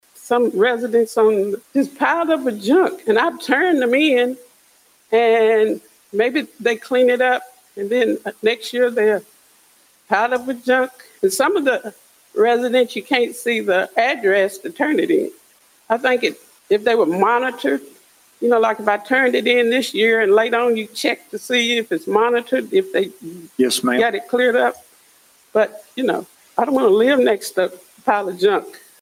Several community members shared thoughts on how the City of Hopkinsville can better serve residents next year. Mayor JR Knight held a community budget discussion prior to this year’s budget allocation process.